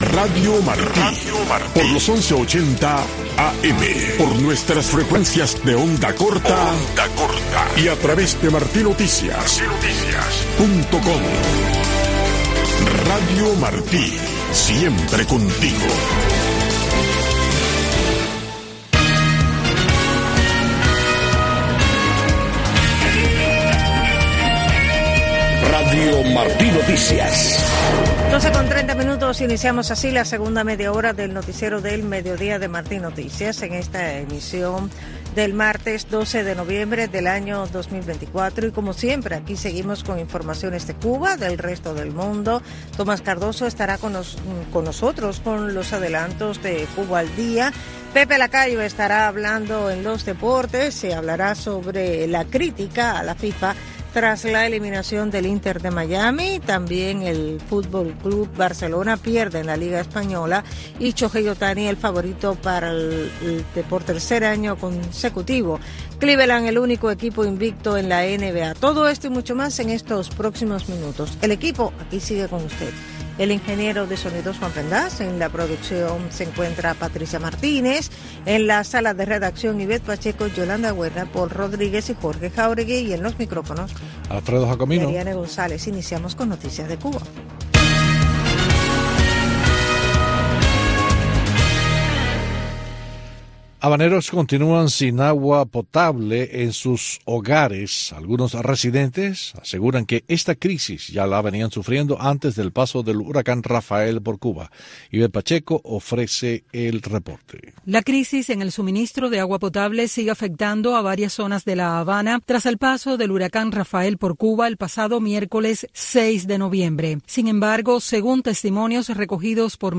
Noticiero de Radio Martí 12:00 PM | Segunda media hora